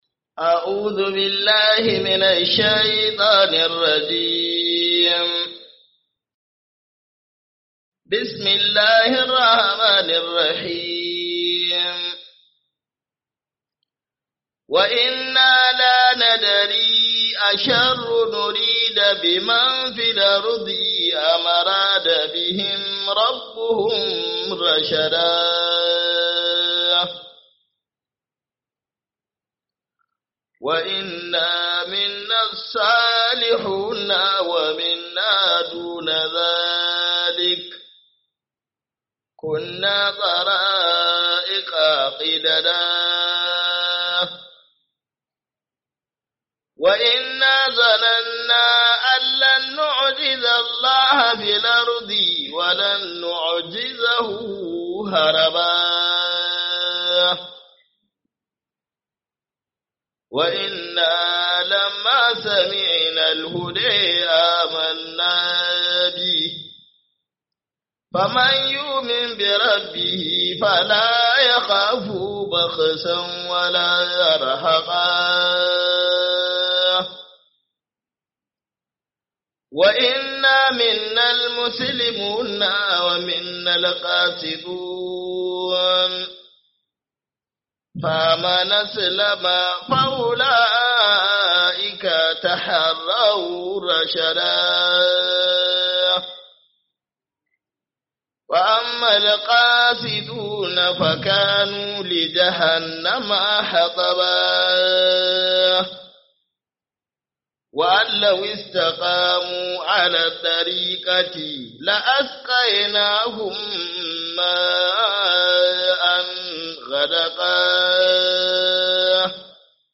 Ramadan Tafsir